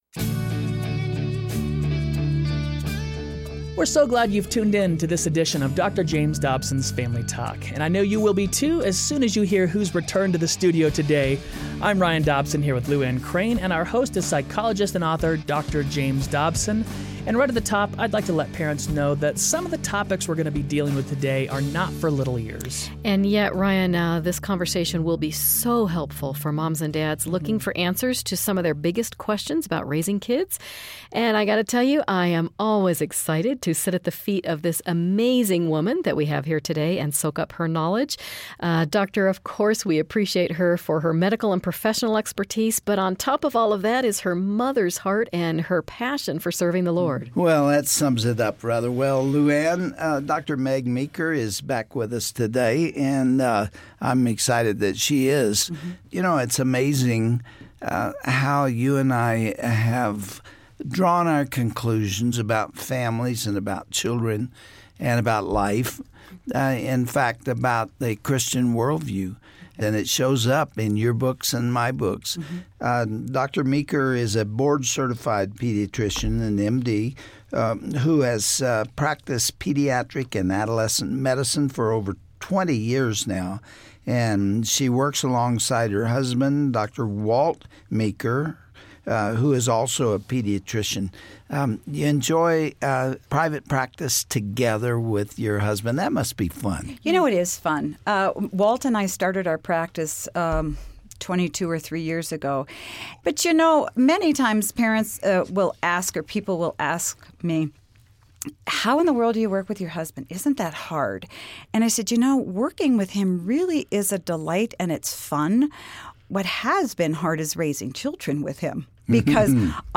Dr. James Dobson and his guest, pediatrician and author, Dr. Meg Meeker, tackle topics like discipline, potty training, and handling the strong-willed child. Get answers to some of the most foundational parenting questions.